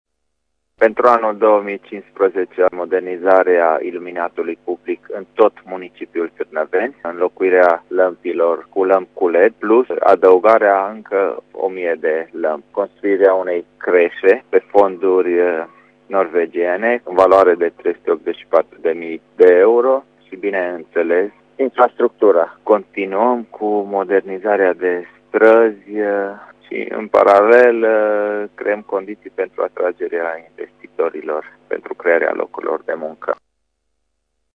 La Târnăveni, priorităţile sunt reabilitarea iluminatului public si a unor străzi, dar şi construirea unei creşe, spune primarul Sorin Meghesan.